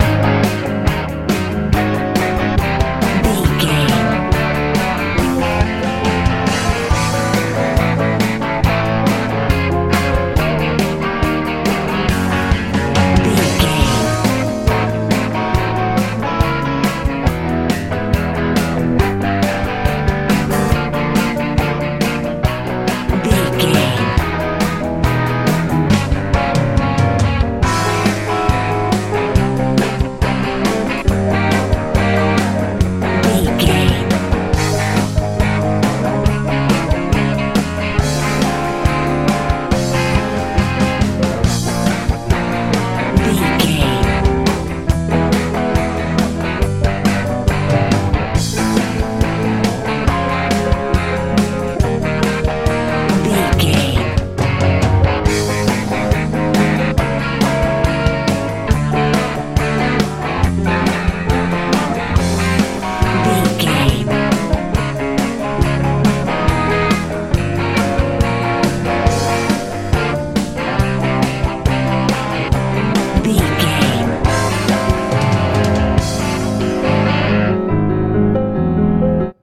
rock feel
Ionian/Major
A♭
joyful
hopeful
piano
electric guitar
bass guitar
drums
80s
90s
lively
energetic